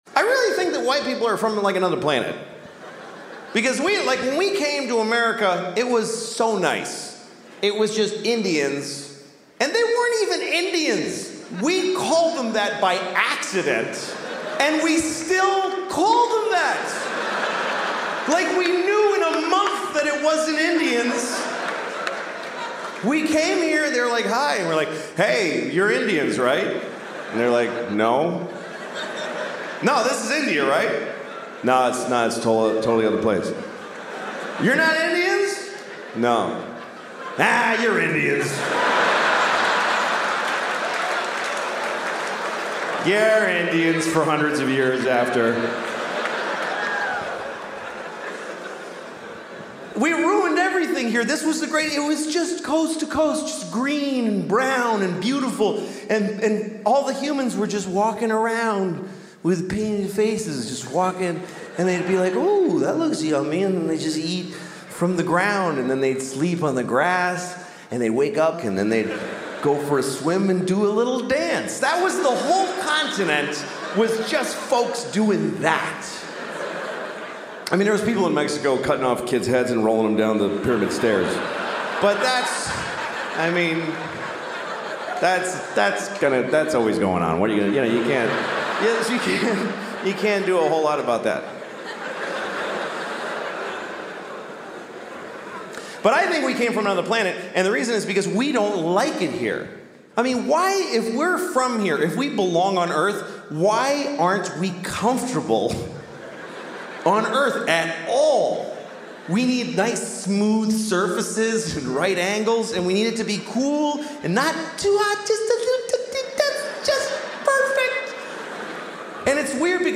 louis-ck-if-god-came-back1.mp3